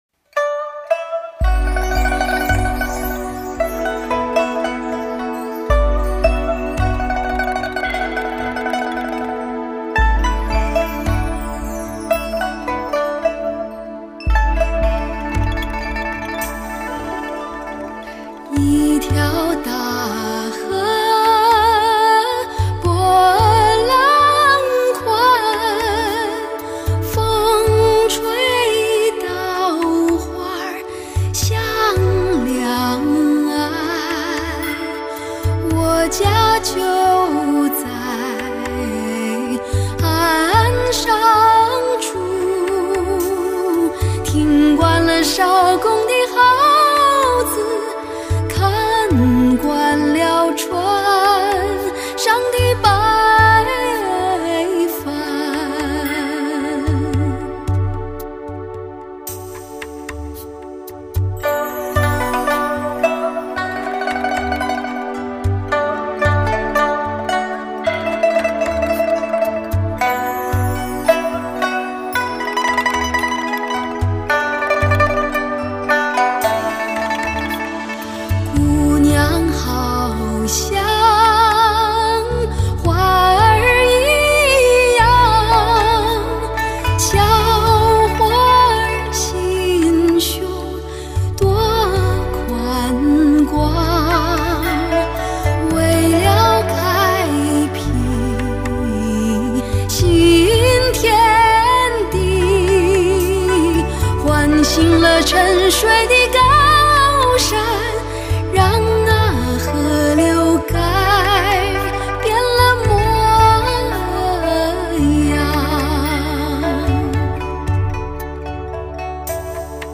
类型: 天籁人声
灵巧与华丽如器乐般歌声